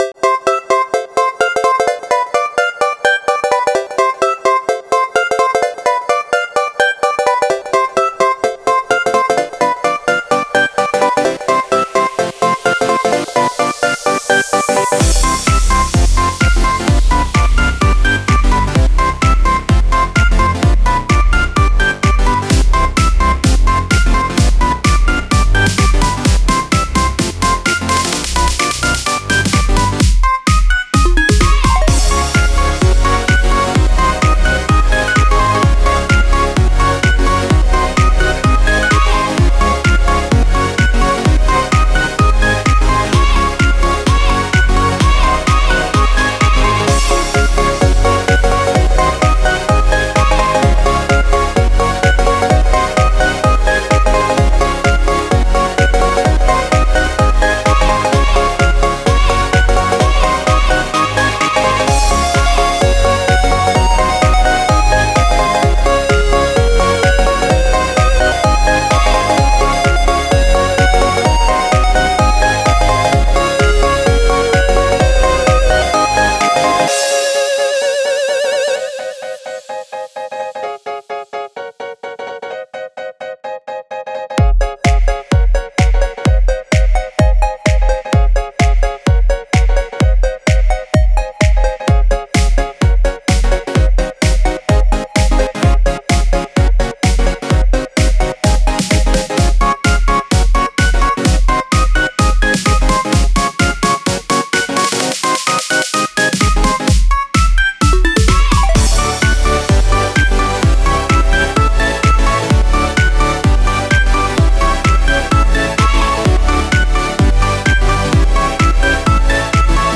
よく使われているHeyという音源を発見したので作りました。